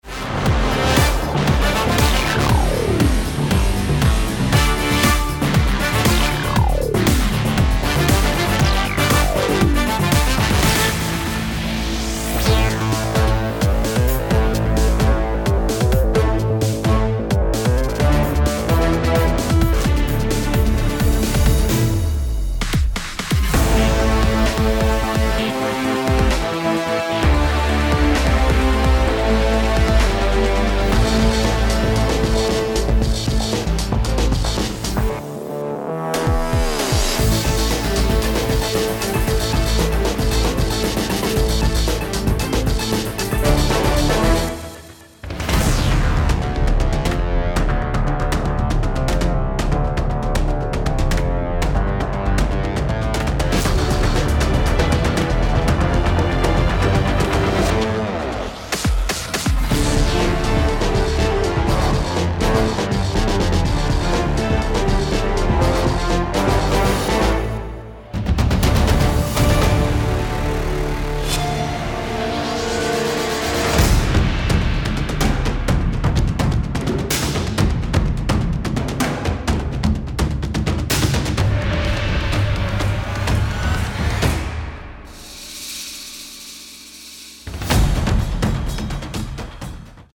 original score
full of comedic fun and heroic excitement